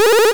powerUp.wav